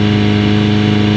Engines